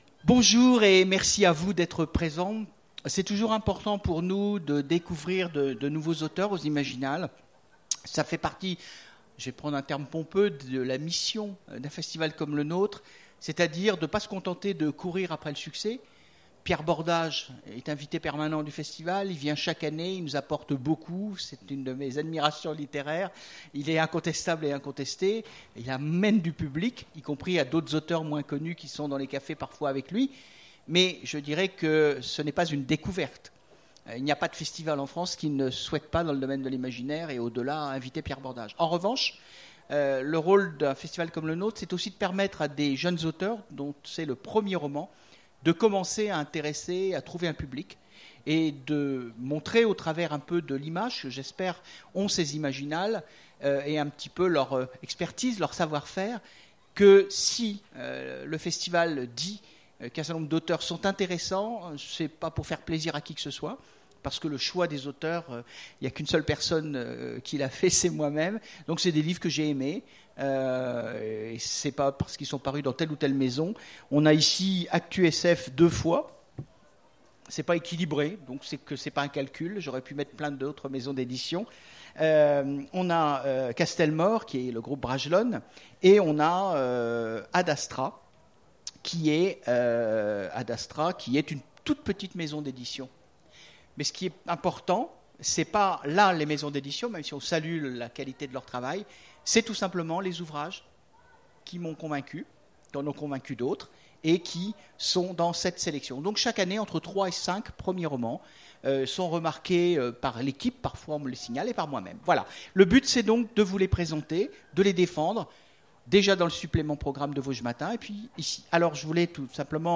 Imaginales 2016 : Conférence Premiers romans…
Mots-clés Rencontre avec un auteur Conférence Partager cet article